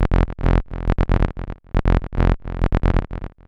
RAZZ BASS -R.wav